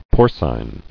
[por·cine]